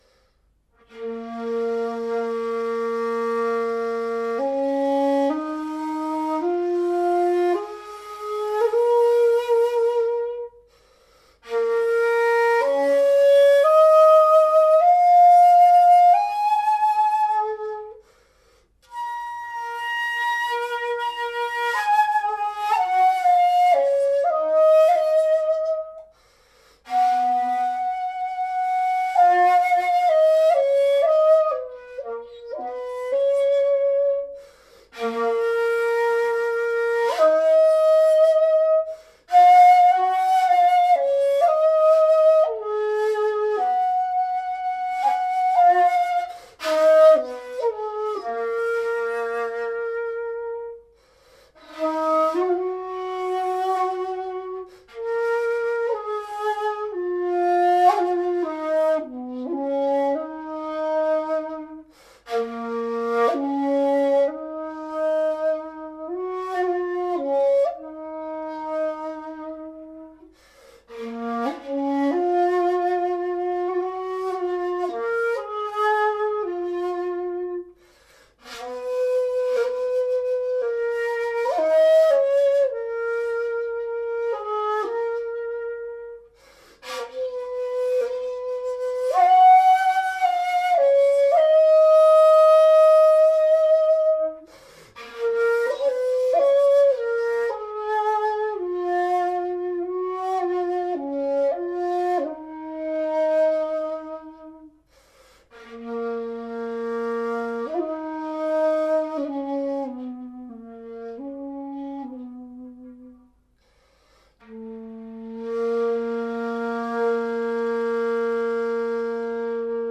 Shakuhachi restauré - Ji-ari Kinko 7 trous 2.3 en Sib | Atelier Chikudo - Shakuhachi